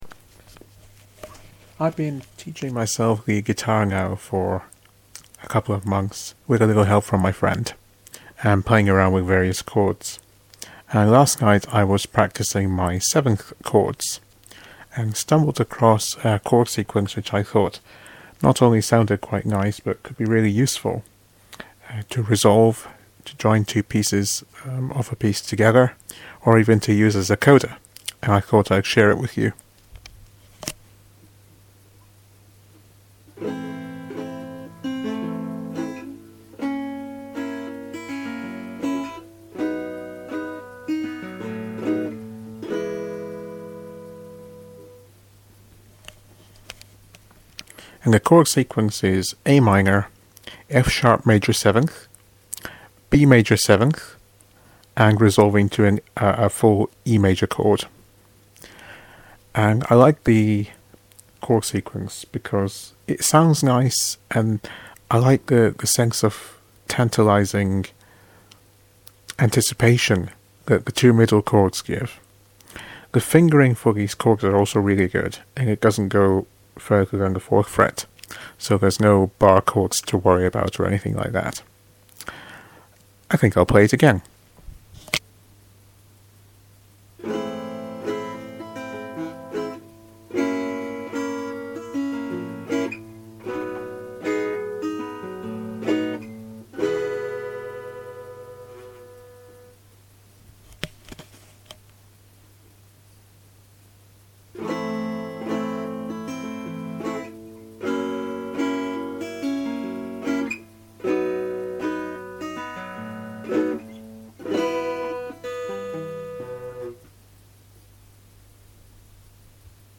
Interesting Guitar Chord Sequence #1
Short but nice guitar chord sequence using 7th chords.